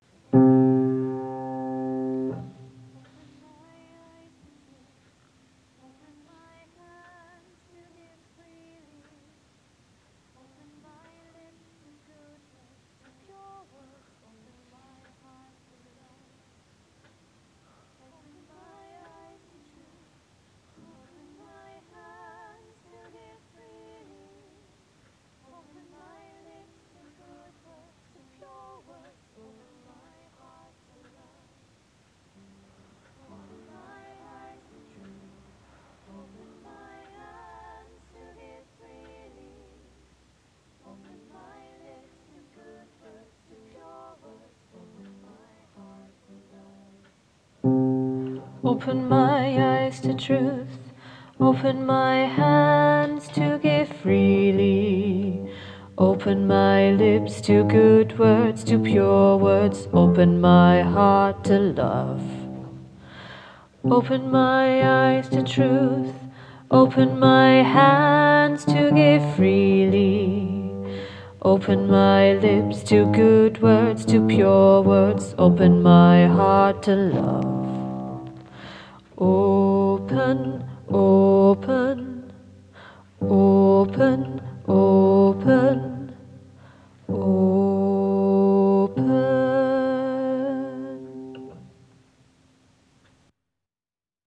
Open-Bass-Lead
Open-Bass-Lead.m4a